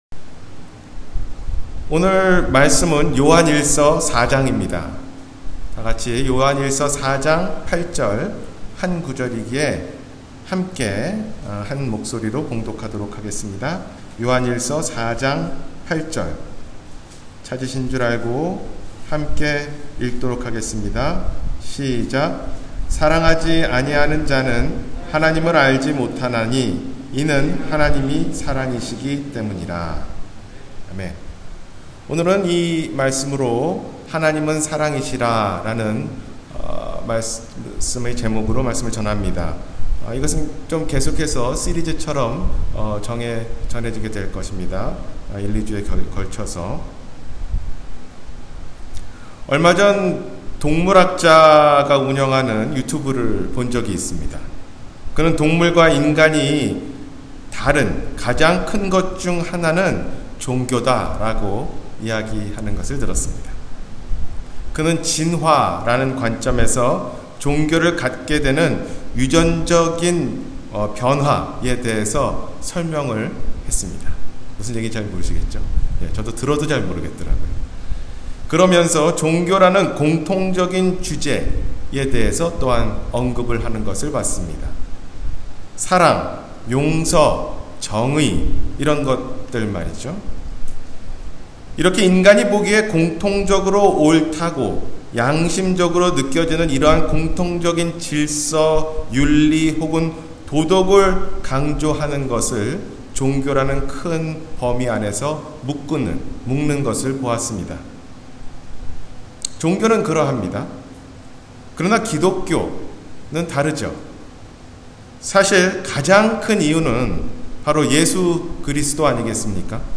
하나님은 사랑이시라 – 주일설교